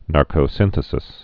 (närkō-sĭnthĭ-sĭs)